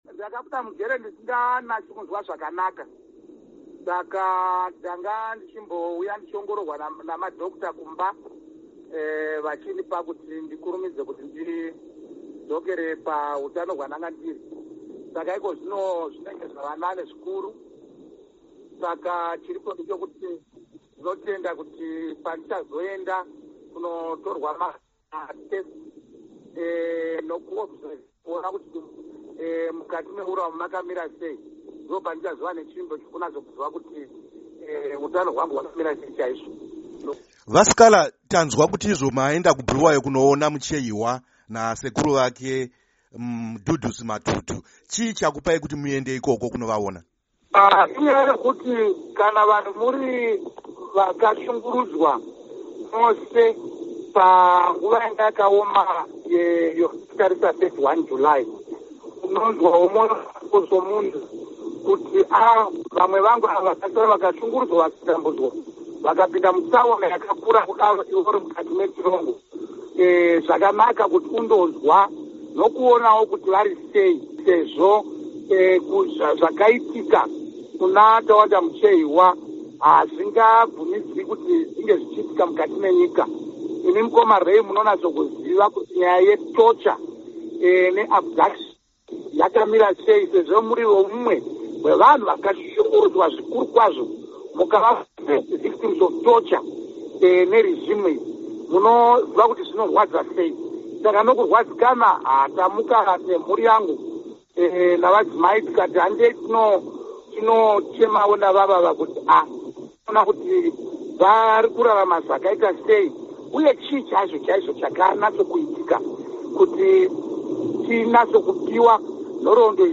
Hurukuro naVaJob Sikhala